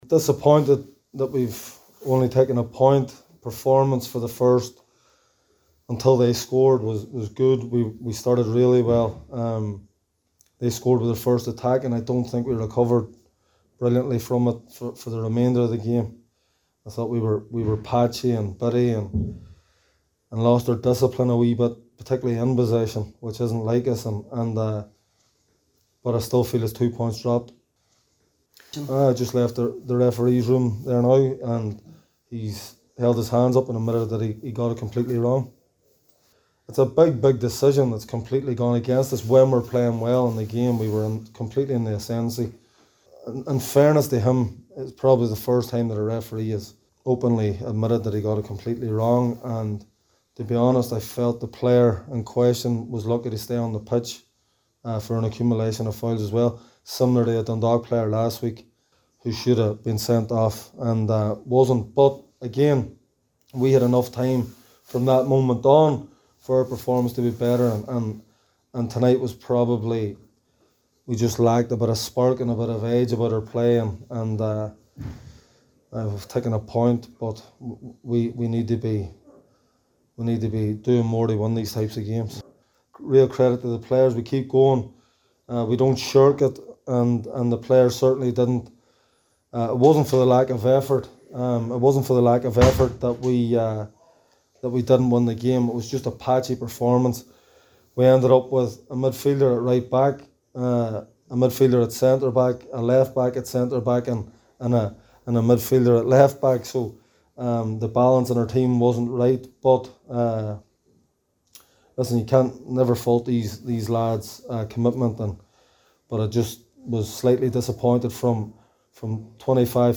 After the match, the City boss gave his thoughts